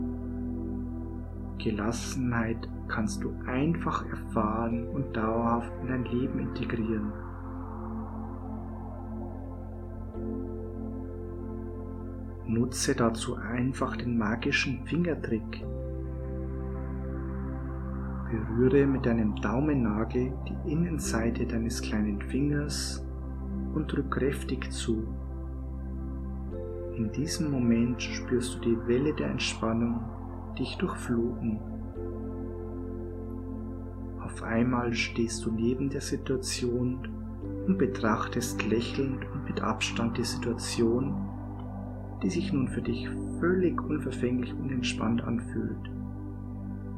Unsere professionell aufgenommenen Hypnosessitzungen führen Sie in einen tiefen Entspannungszustand, in dem Sie sich von Sorgen und Anspannungen befreien können.
In diesem Paket sind vier geführte Entspannungshypnosen ernthalten: zwei Hypnosen dienen zum Kraft schöpfen für den Alltag, zwei weitere Hypnosesitzungen konzentrieren sich auf die Unterstützung für mehr Gelassenheit und zur Stressbewältigung.